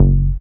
05SYN.BASS.wav